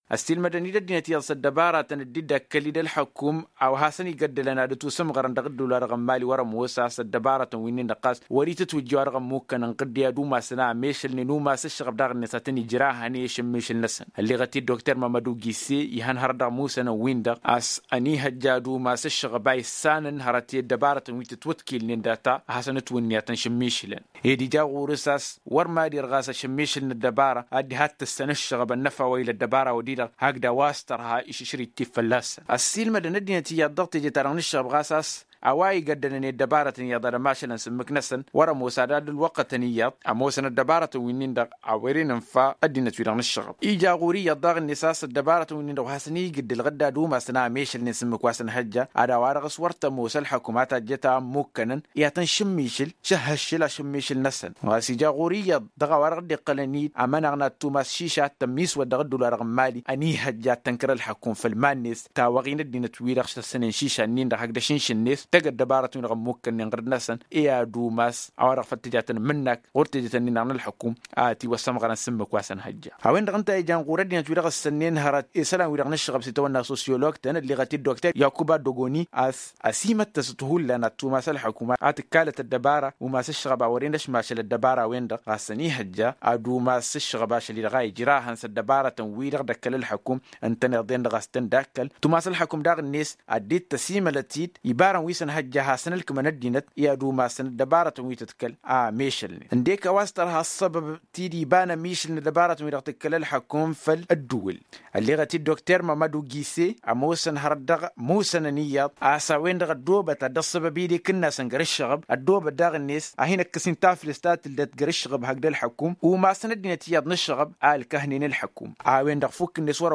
Réponses dans ce reportage